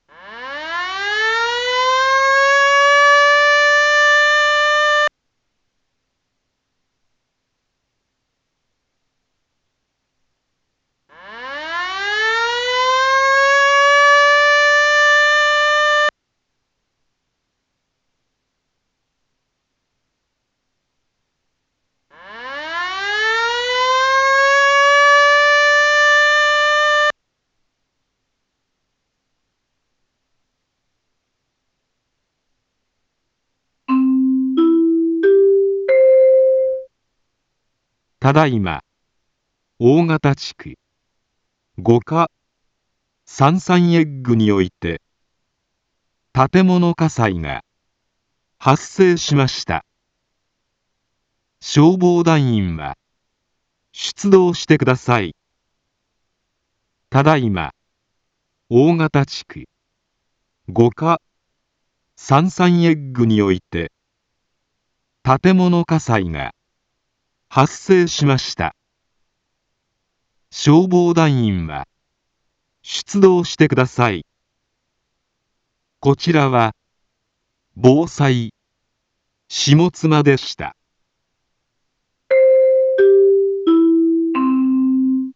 Back Home 一般放送情報 音声放送 再生 一般放送情報 登録日時：2023-04-29 14:29:29 タイトル：火災報 インフォメーション：ただいま、大形地区、五箇、サンサンエッグにおいて、 建物火災が、発生しました。